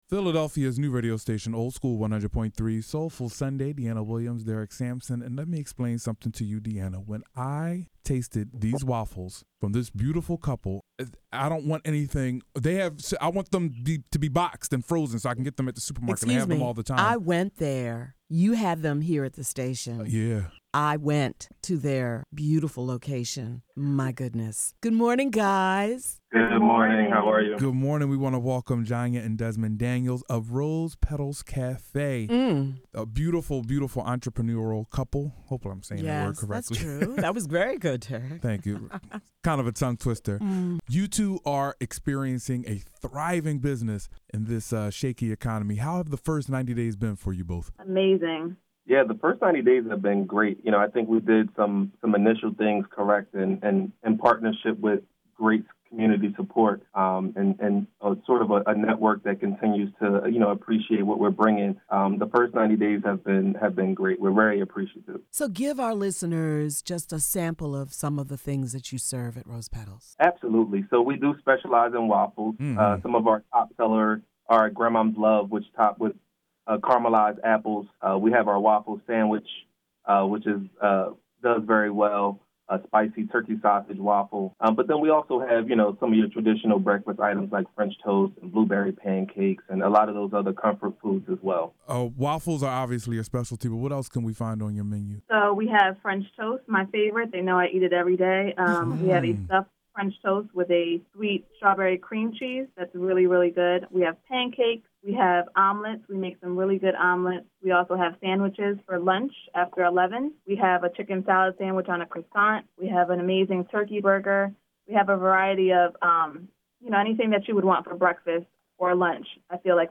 chat with entrepreneurs